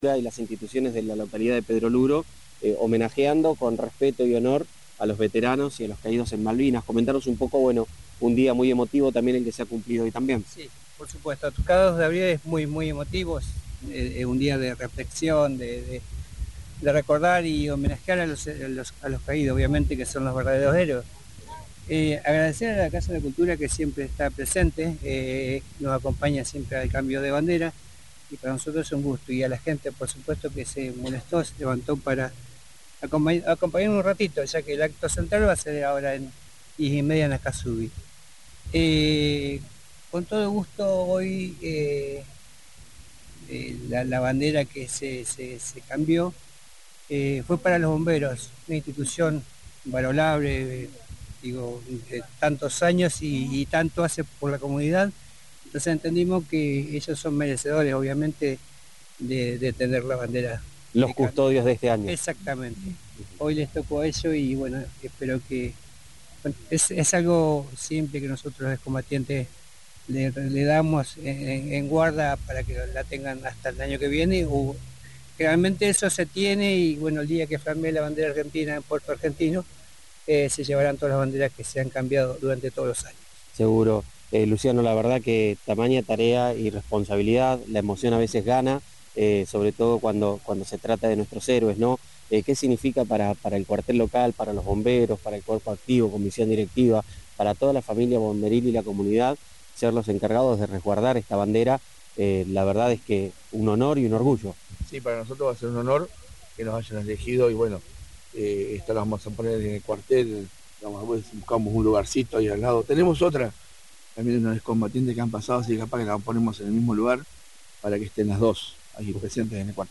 El Municipio de Villarino conmemoró el Día del Veterano y de los Caídos en la Guerra de Malvinas con un emotivo acto oficial realizado en Hilario Ascasubi.